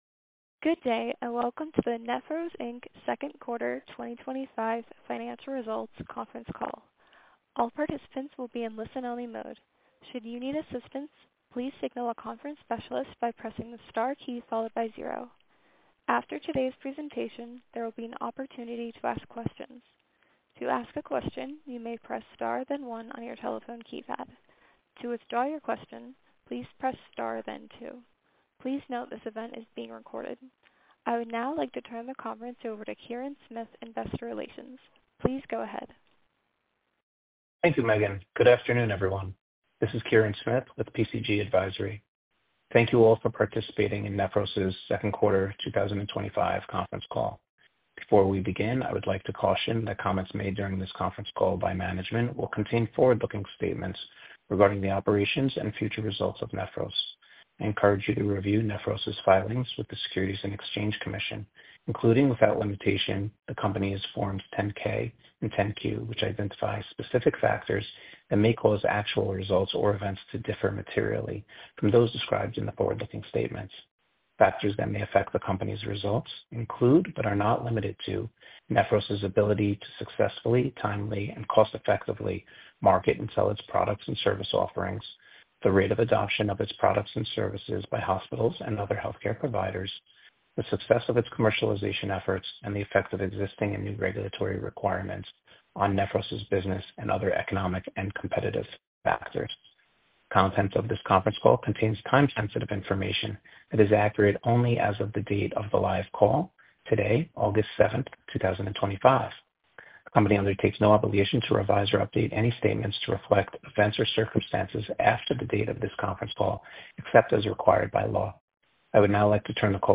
Nephros Q2 2025 Conference Call Replay from August 07, 2025
Listen to the replay of the Q2 2025 conference call